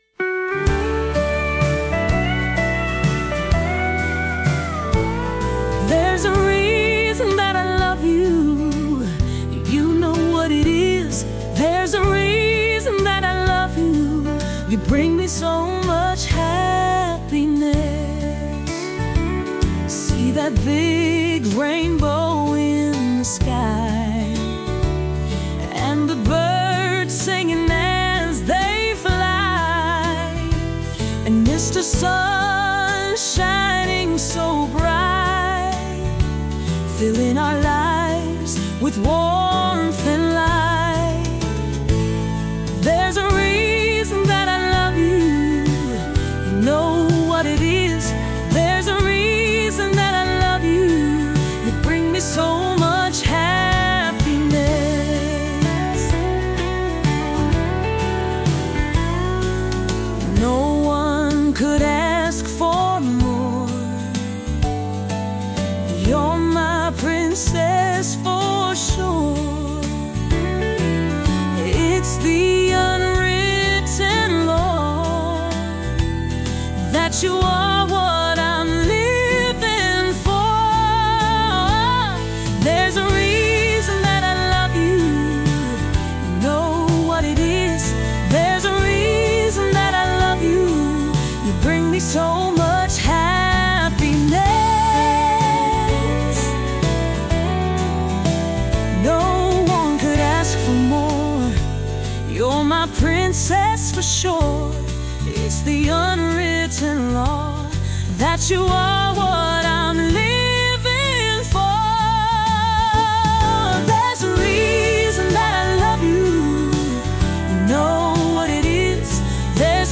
female led Country
heartfelt country love song